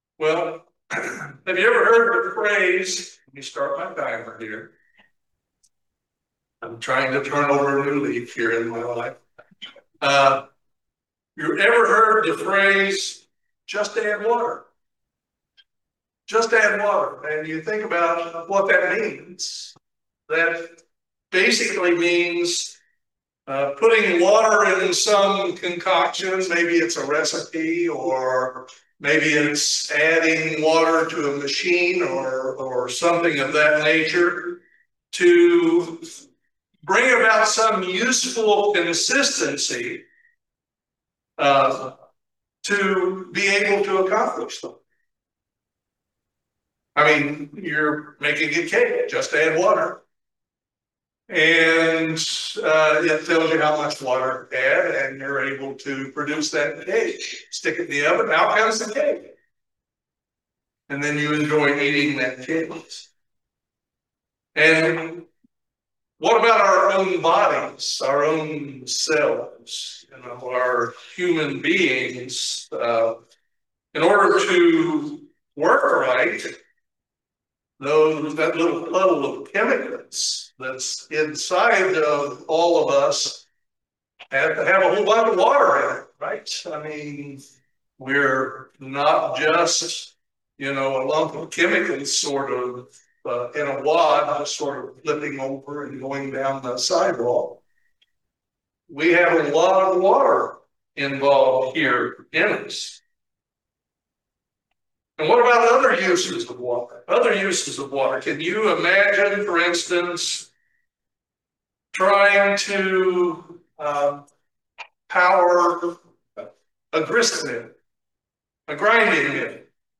Join us for this excellent video sermon on the subject of God's Holy Spirit.
Given in Lexington, KY